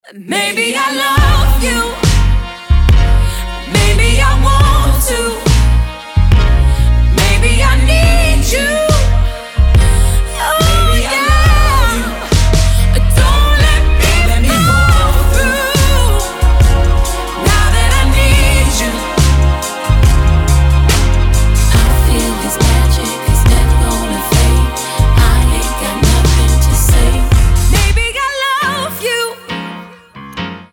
• Качество: 320, Stereo
громкие
женский вокал
RnB
soul
Neo Soul